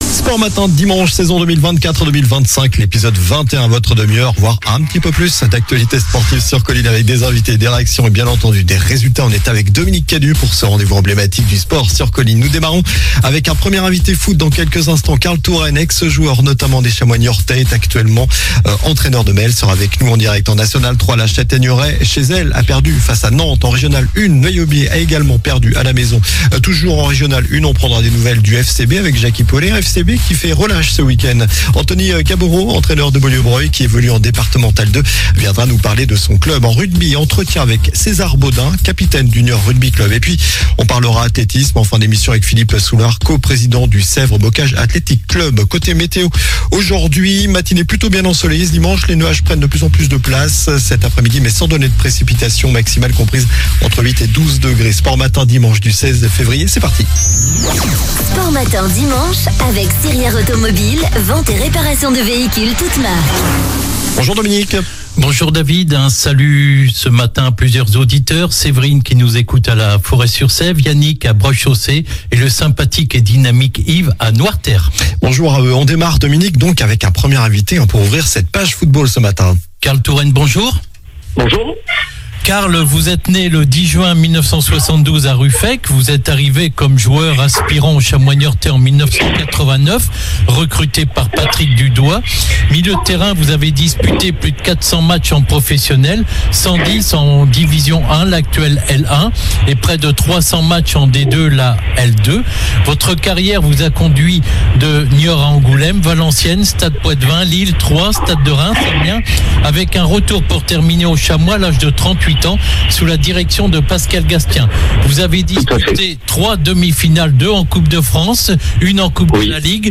COLLINES LA RADIO : Réécoutez les flash infos et les différentes chroniques de votre radio⬦
En rugby, entretien